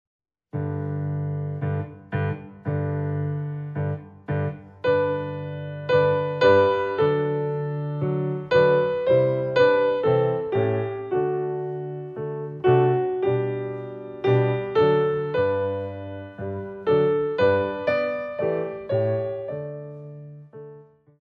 Pavane
Ballet Class Music For First Years of Ballet